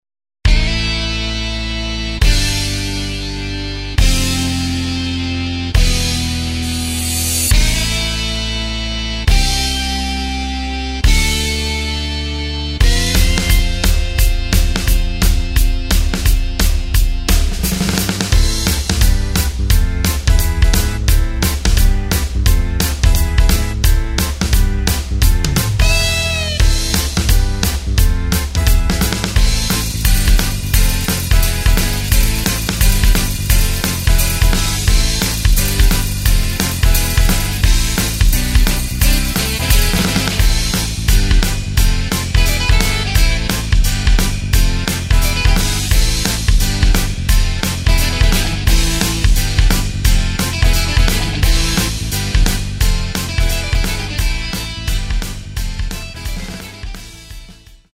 Takt:          4/4
Tempo:         136.00
Tonart:            Am
Playback mp3 Demo